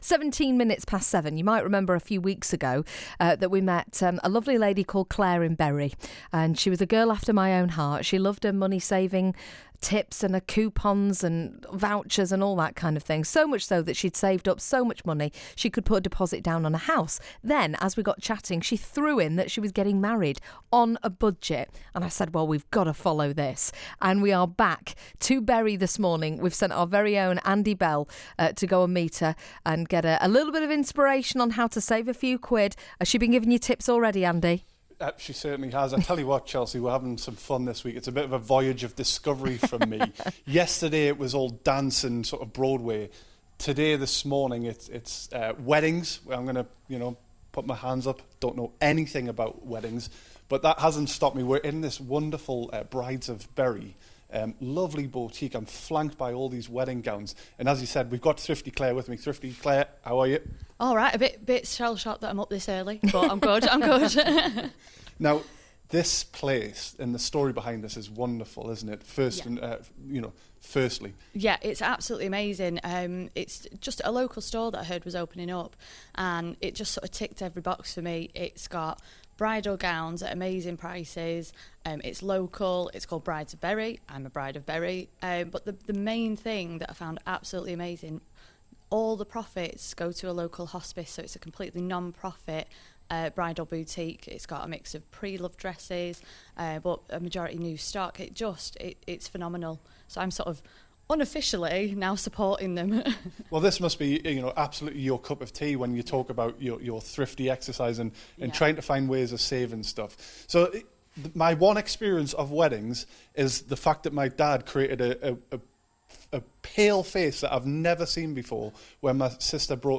Interview part 1: